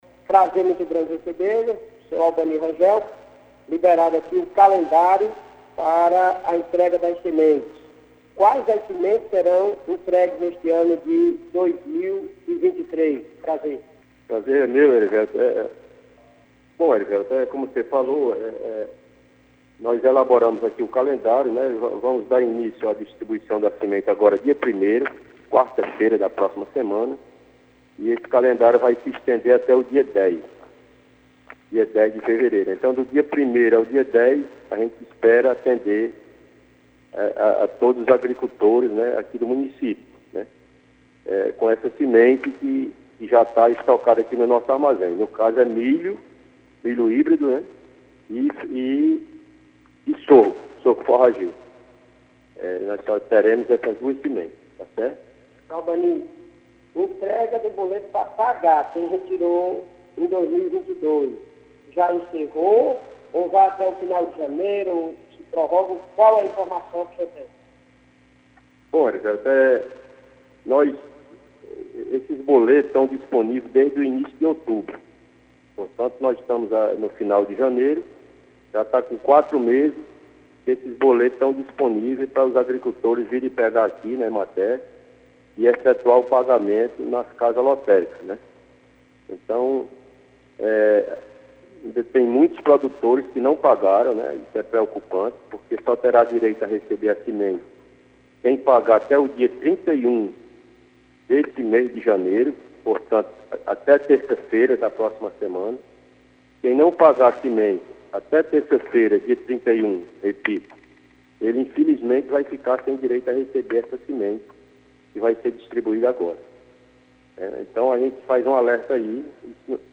Em entrevista à Rádio Campo Maior AM 840